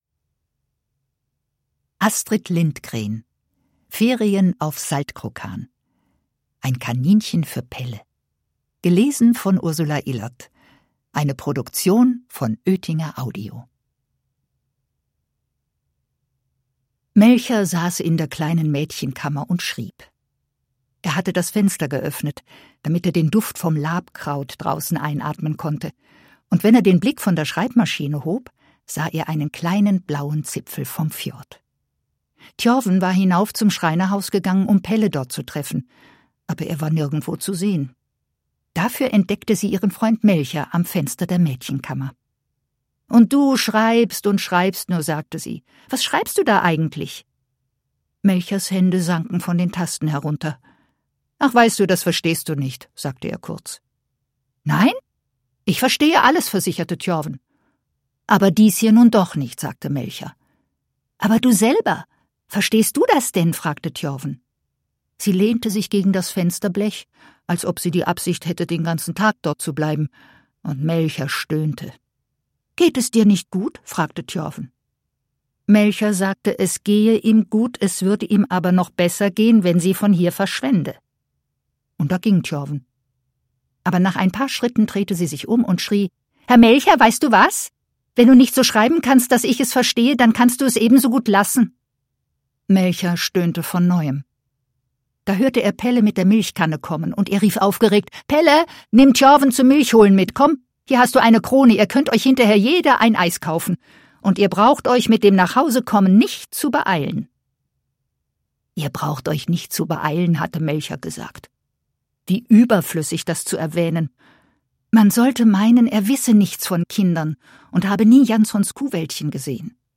Hörbuch: Ferien auf Saltkrokan.